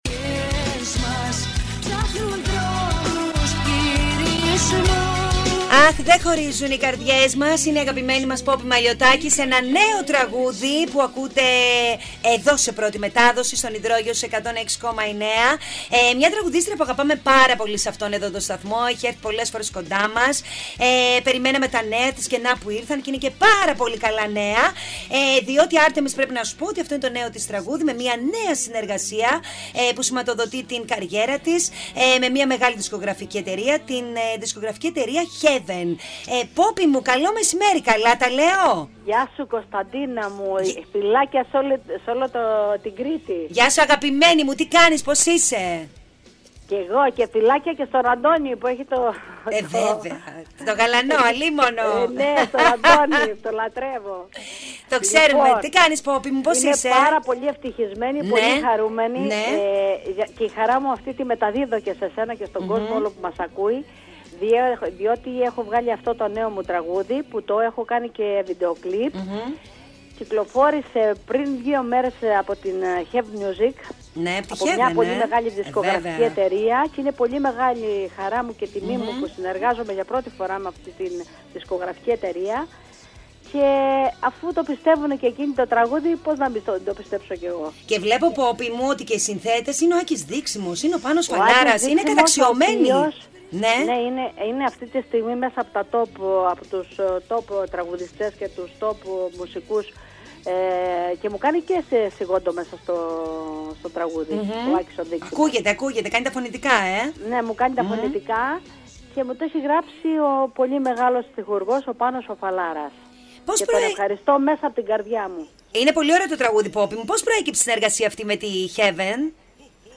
Η Πόπη Μαλιωτάκη Στον Υδρόγειο 106,9 (τηλεφωνική συνέντευξη) 19/3/2018